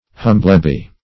Humblebee \Hum"ble*bee`\, n. [OE. humbilbee, hombulbe; cf. D.